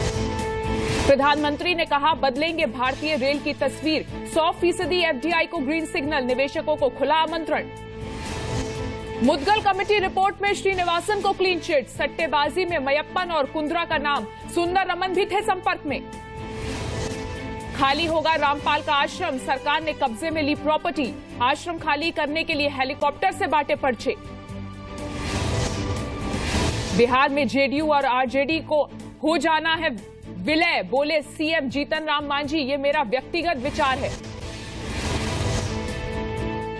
Headlines @ 3:30 pm